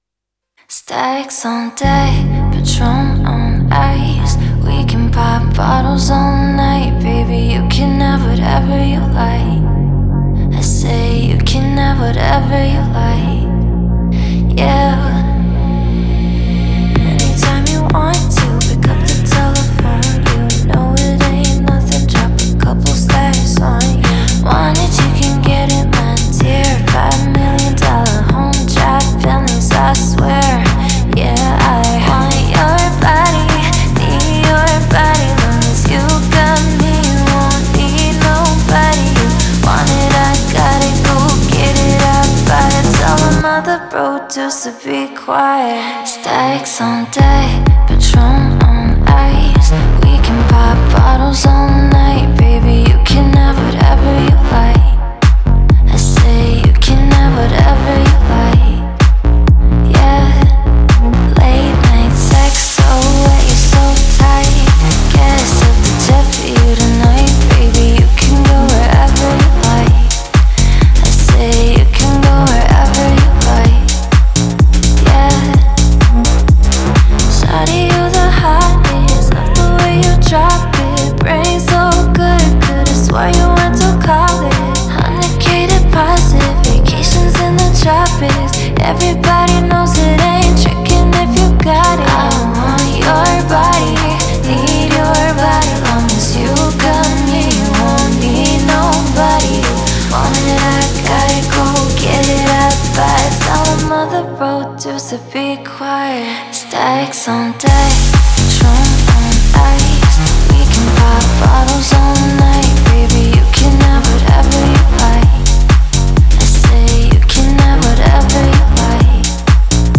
это яркая и энергичная композиция в жанре R&B